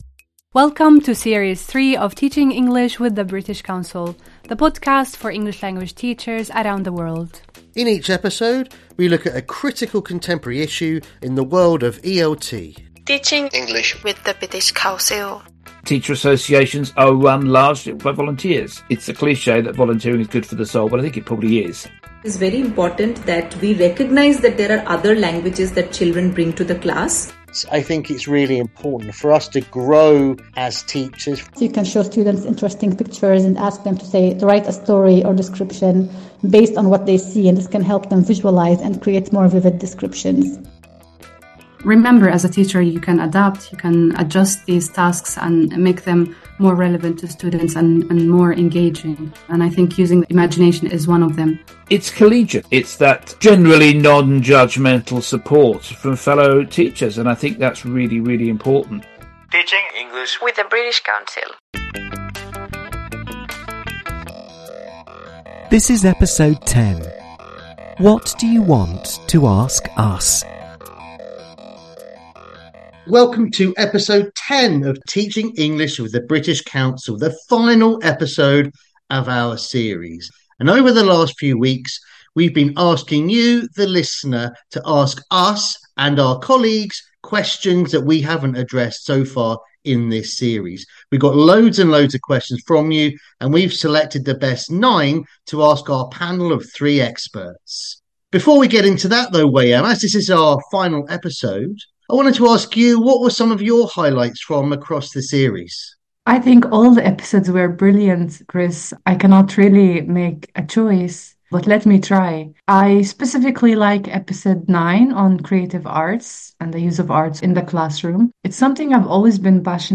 Podcast with experts in English language teaching discussing teacher talk, the learning styles myth, dealing with fast finishers and multilingual classes.
They also talk to a panel of ELT experts, who answer the questions from you, our TeachingEnglish community listeners.